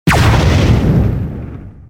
ships / combat / otherhit1.wav
otherhit1.wav